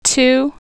too  u
As in tū (too), blū (blue)
two.wav